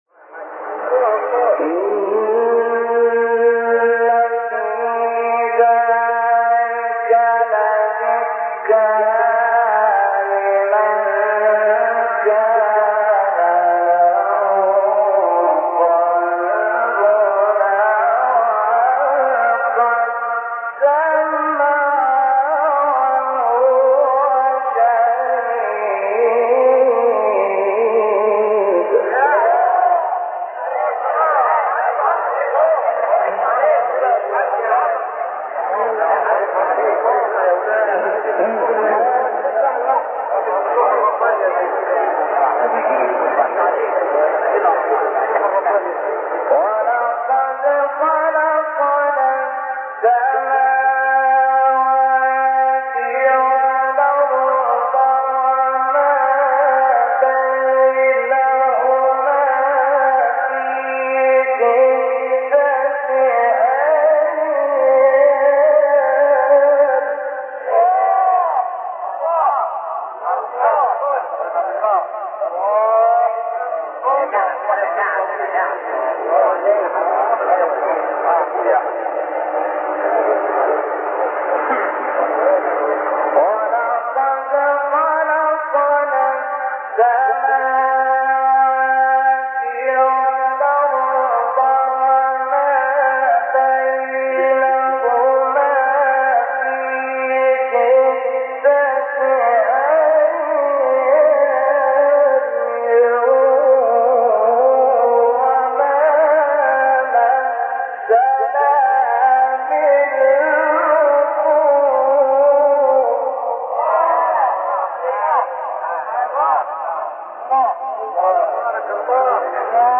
آیه 37-42 سوره ق استاد شحات | نغمات قرآن | دانلود تلاوت قرآن